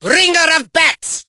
mortis_ulti_vo_04.ogg